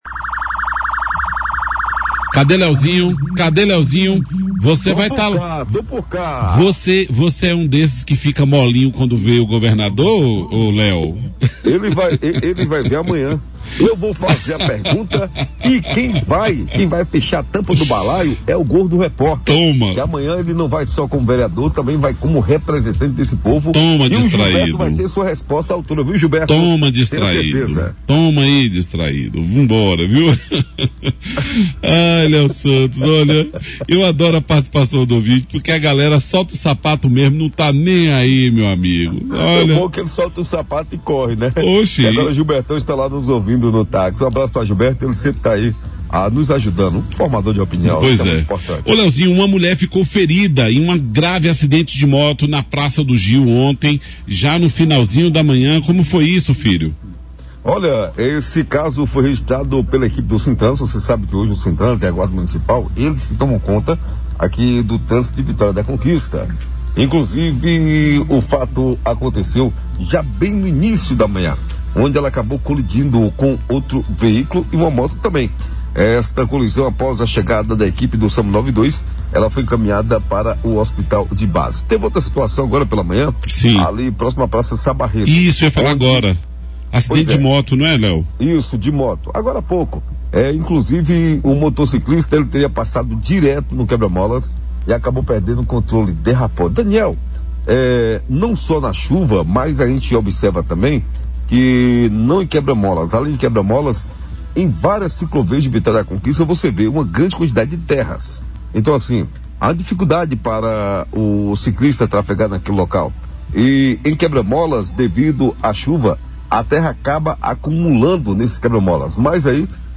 Somente nas últimas 24 horas foram dois resgates de vítimas. Na reportagem do Brasil Notícias, via Rádio Brasil, desta quinta-feira (27) trouxe detalhes dessas e de outras ocorrências registradas nas Delegacias da Joia do Sertão Baiano.